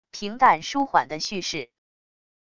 平淡舒缓的叙事wav音频